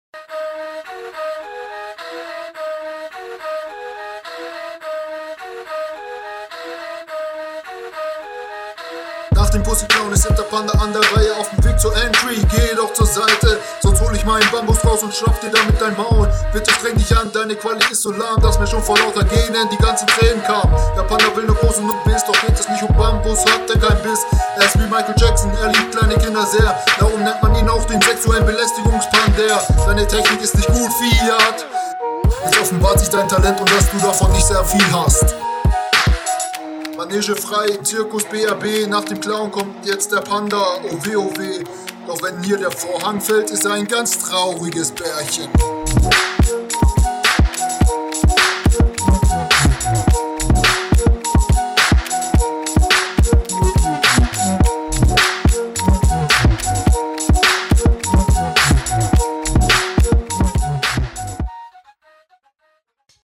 Beat is nice, Einstieg kommt gut, aber danach viel Offbeat und viele Flowfehler.
Flow leider sehr wackelig.
Hier hast du wenigstens anfangs Flow bis du offbeat wurdest.